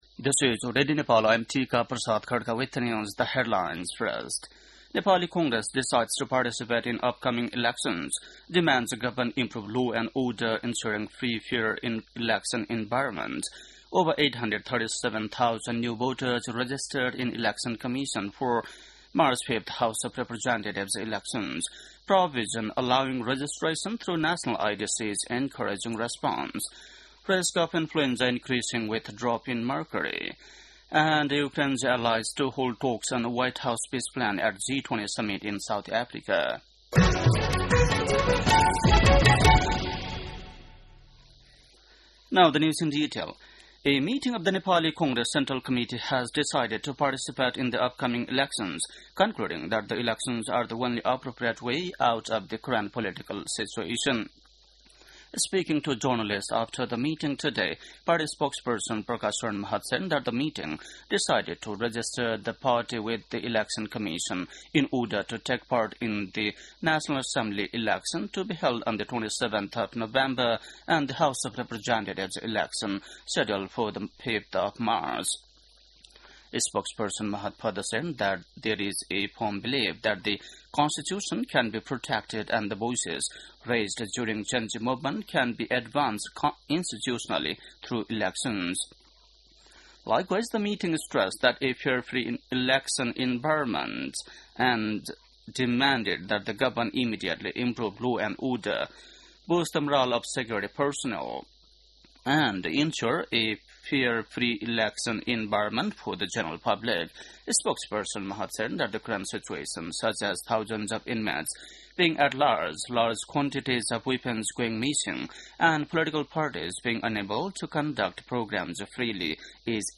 बेलुकी ८ बजेको अङ्ग्रेजी समाचार : ६ मंसिर , २०८२